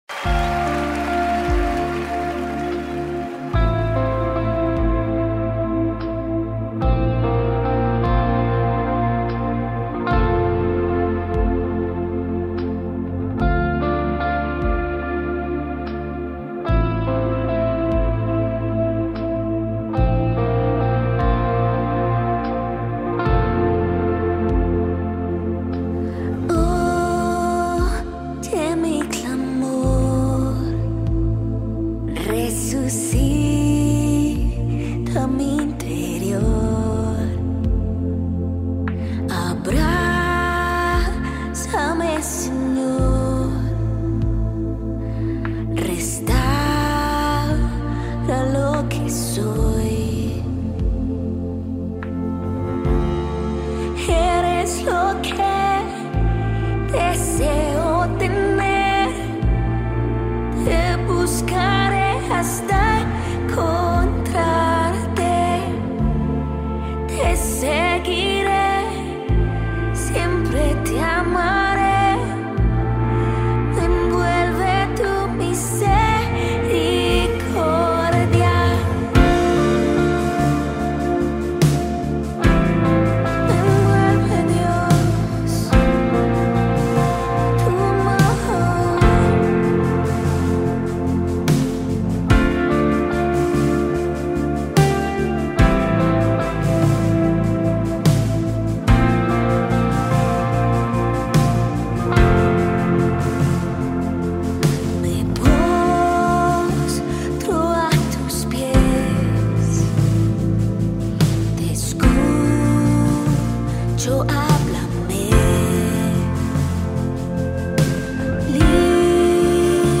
Dm - Am - Bb - F
1380 просмотров 666 прослушиваний 32 скачивания BPM: 73